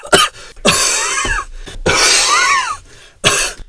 Player_cough.ogg